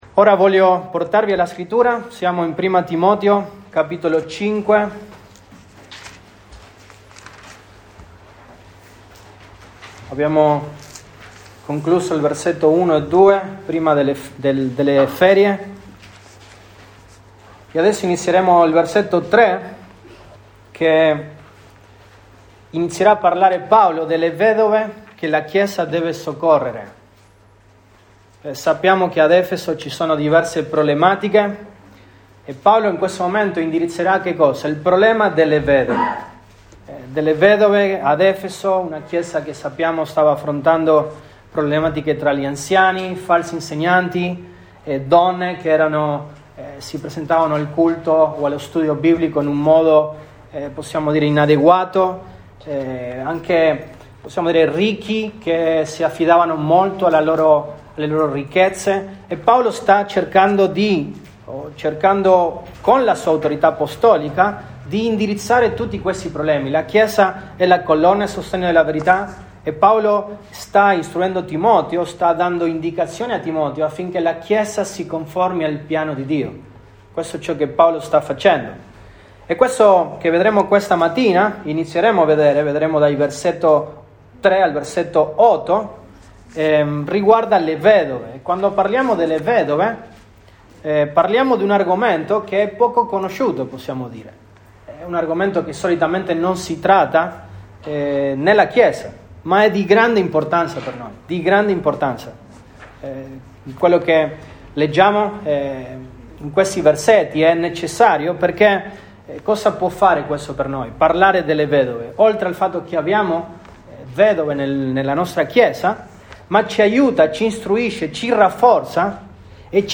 Set 19, 2021 Le vedove che la chiesa deve soccorrere, 1° parte MP3 Note Sermoni in questa serie Le vedove che la chiesa deve soccorrere, 1° parte.